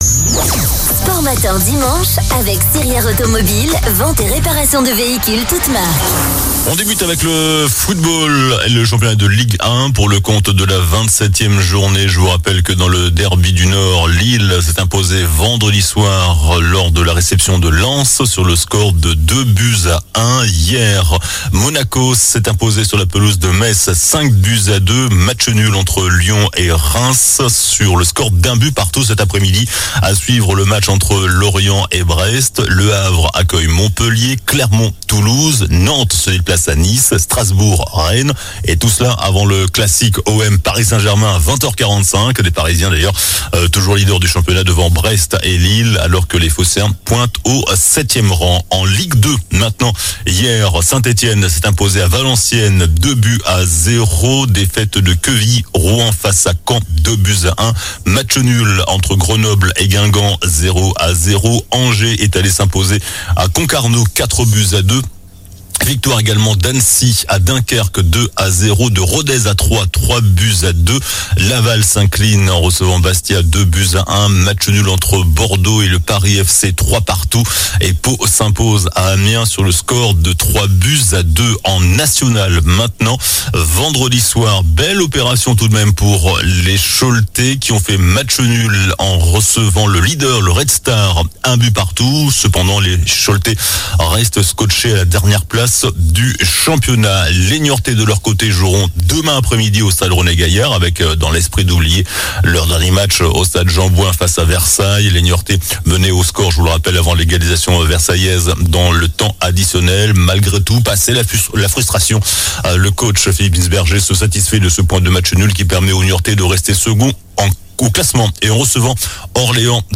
Le multiple médaillé en natation Camille Lacourt livre son sentiment sur Léon Marchand nouveau prodige de la discipline. 0:00 29 min 11 sec